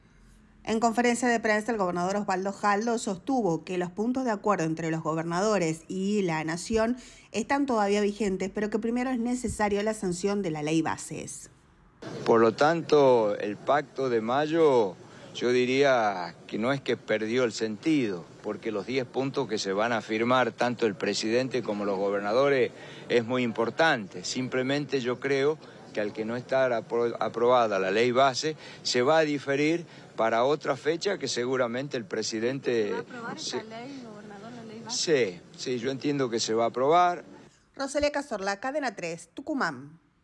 Así lo afirmó Osvaldo Jaldo en una conferencia de prensa.